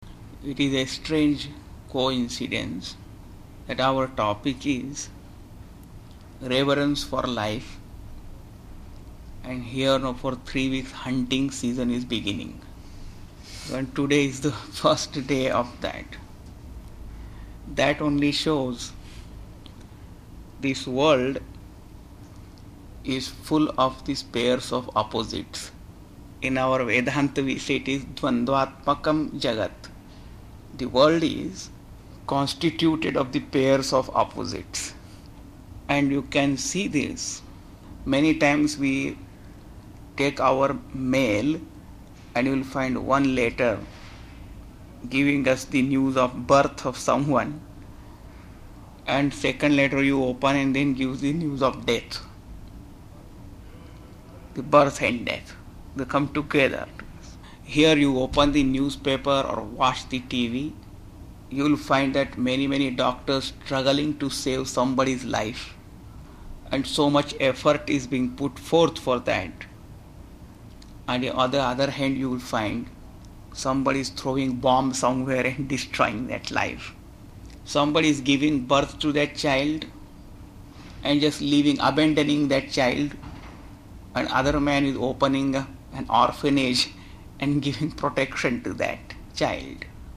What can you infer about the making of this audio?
Reverence of Life, Talk at Buffalo, USA in 1988. The world we live in, is full of pairs of opposites. To live a life of peace and joy, one must rise above these pairs.